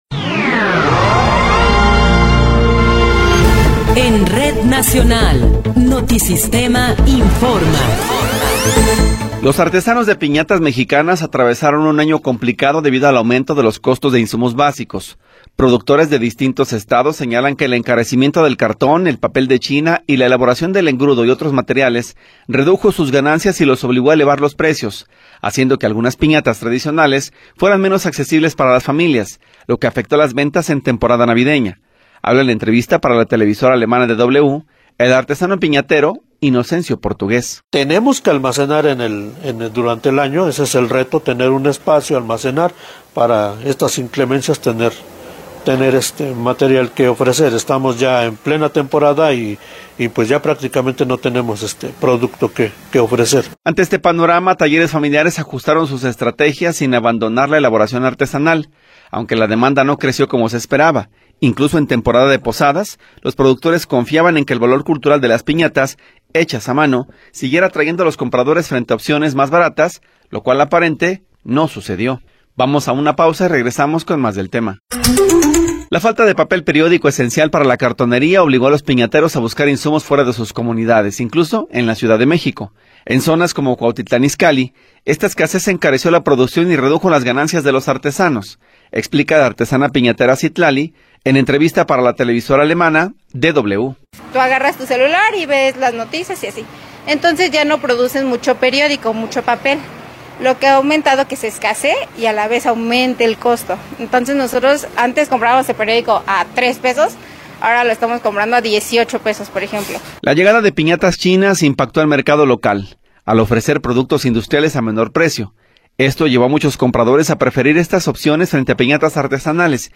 Noticiero 16 hrs. – 1 de Enero de 2026
Resumen informativo Notisistema, la mejor y más completa información cada hora en la hora.